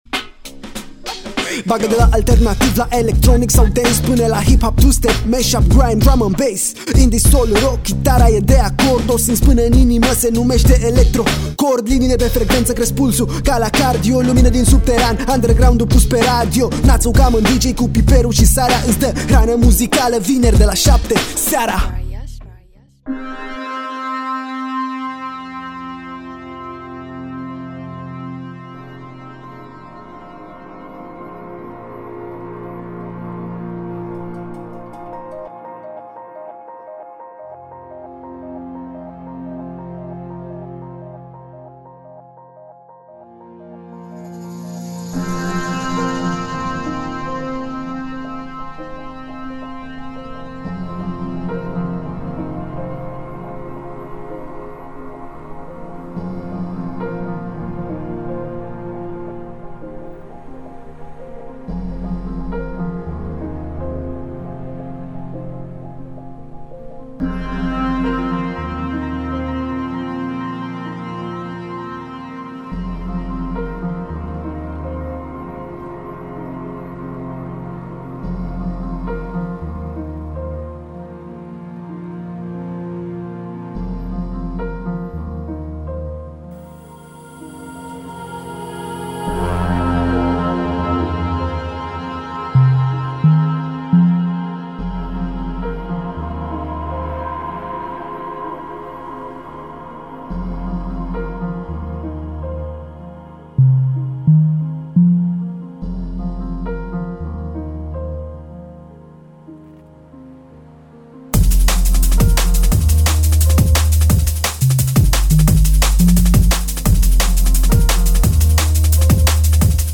un mix de piese numai potrivite pentru începutul de weekend